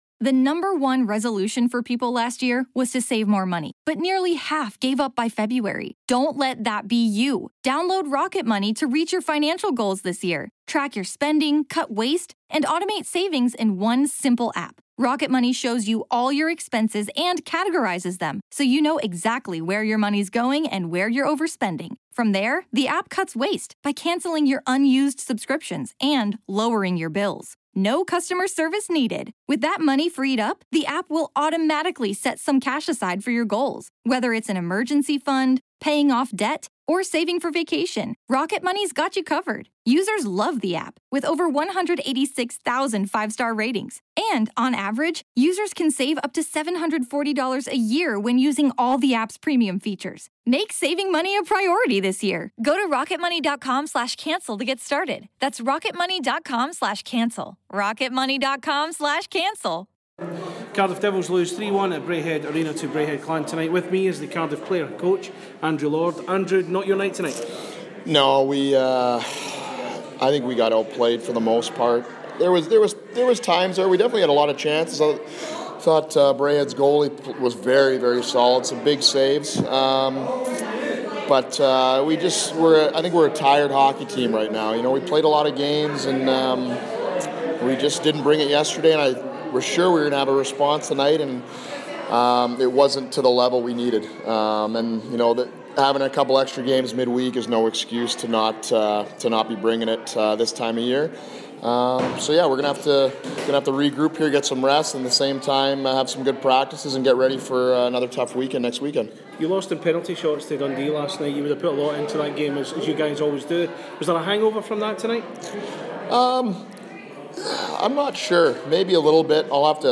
spoke to Clan Radio following tonight's game in Glasgow